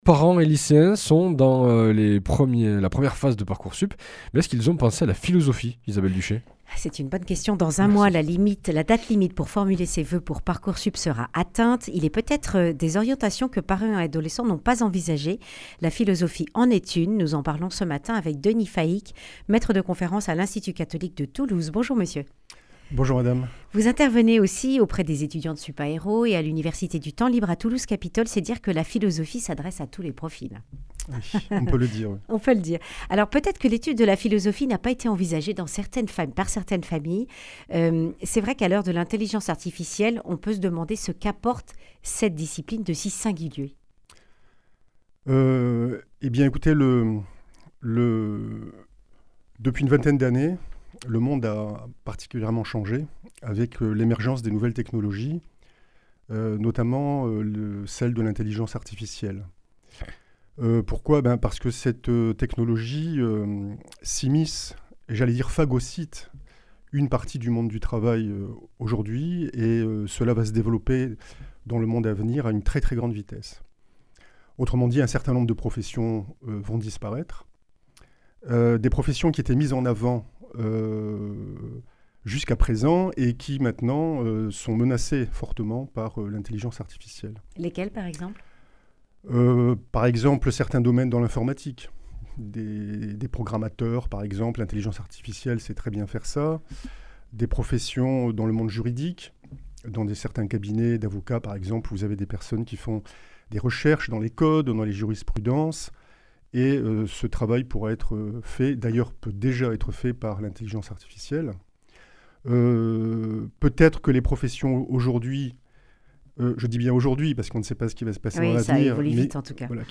Accueil \ Emissions \ Information \ Régionale \ Le grand entretien \ ParcourSup : avez-vous pensé à la philosophie ?